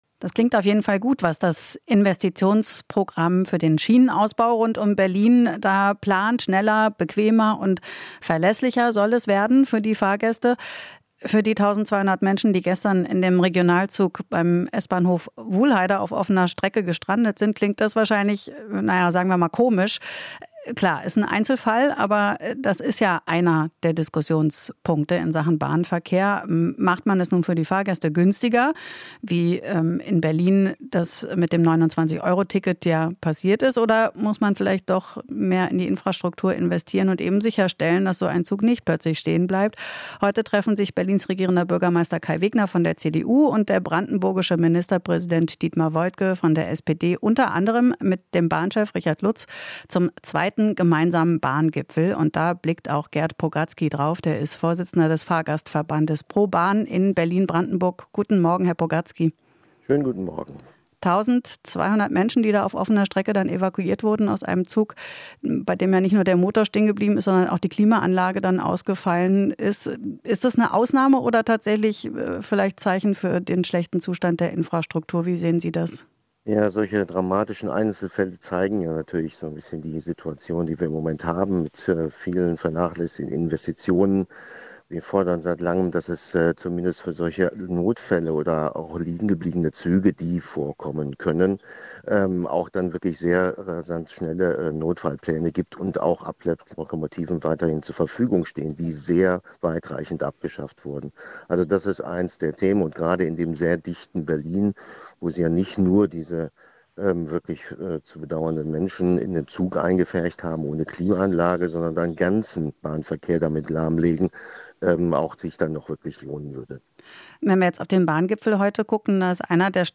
Interview - Fahrgastverband: Bahn hat zu viele vernachlässigte Investitionen